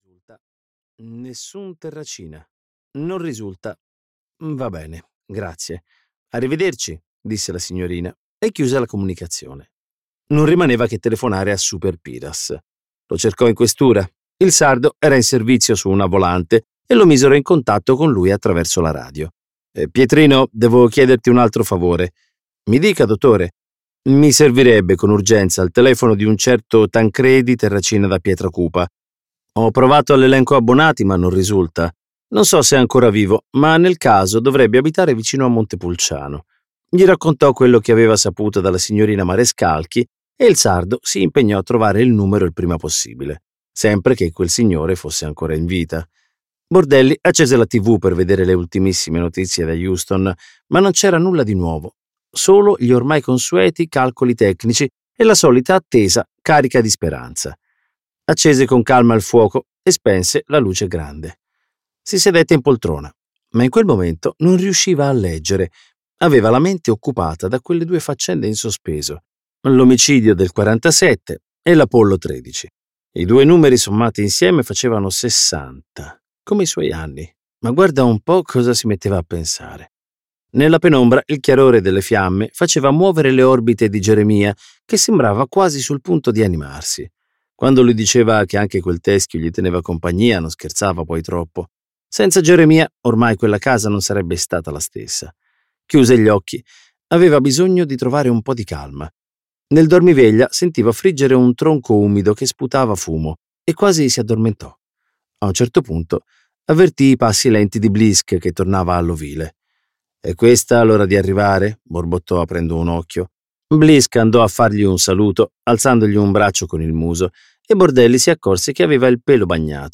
"Non tutto è perduto" di Marco Vichi - Audiolibro digitale - AUDIOLIBRI LIQUIDI - Il Libraio